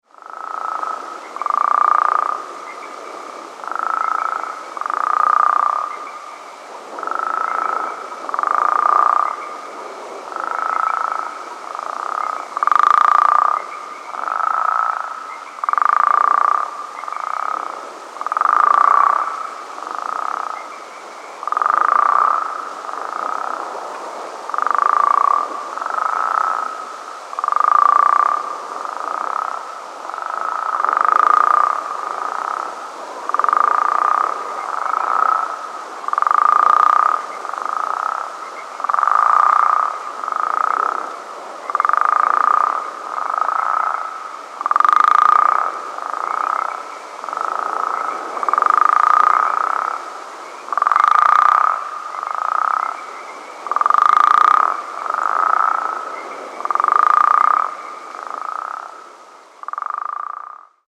The call of the Western Spadefoot is a short loud trill, like a quick snore, lasting less than one second.
The following recordings were made at night in mid March at a distance of 200 - 300 ft. from a creek flowing through Alameda and San Joaquin Counties.
Sound  This is a 60 second recording the advertisement calls of a small group of Western Spadefoots made in Alameda County. Along with the Sierran Treefrogs, a Western Toad can be heard calling in the background.